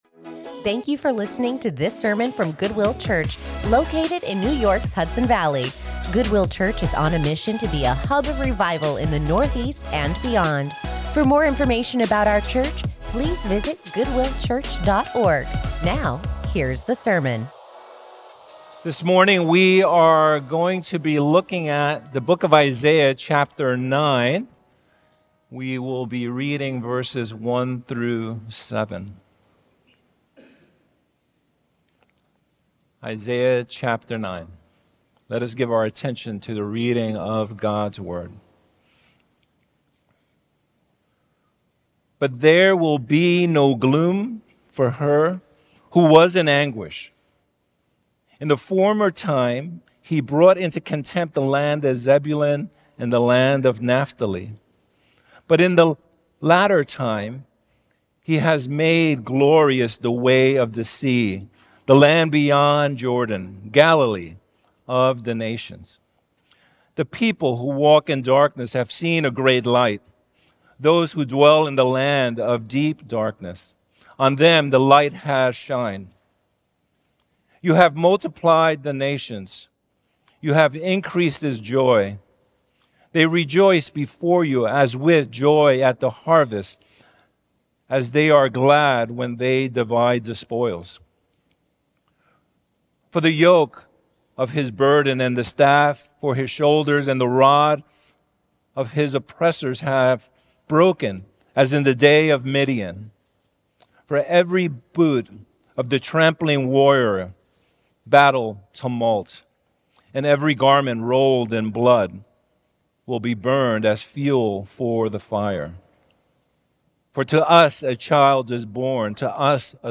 Join us in worship and in the study of God's Word as we take a quick break from our sermon series with this sermon